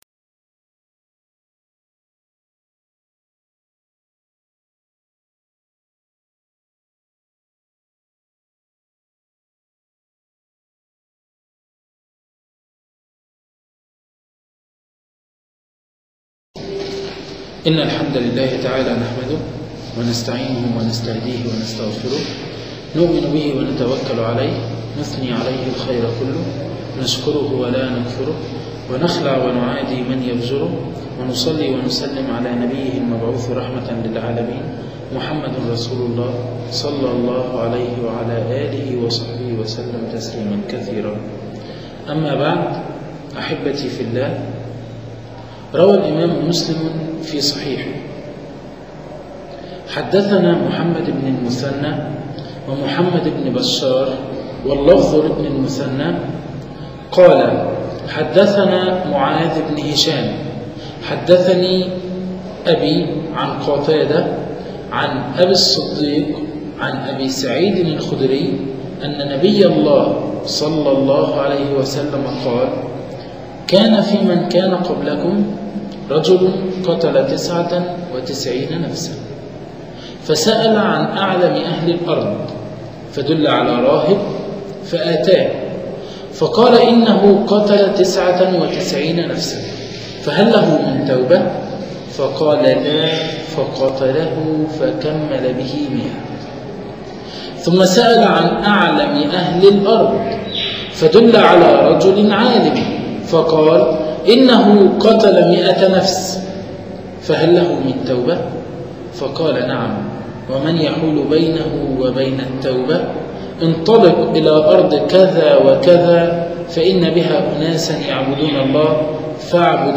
قصة القاتل مائة نفس (محاضرة